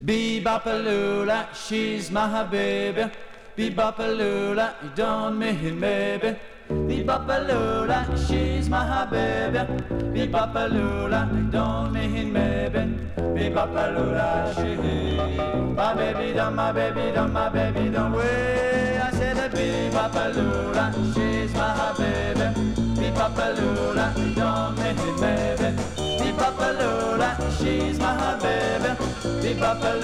Rock'n'roll